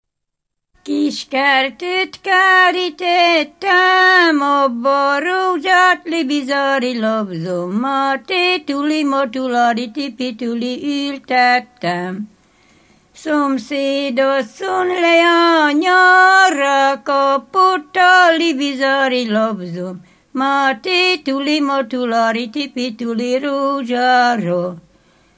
Dunántúl - Somogy vm. - Mesztegnyő
ének
Stílus: 1.1. Ereszkedő kvintváltó pentaton dallamok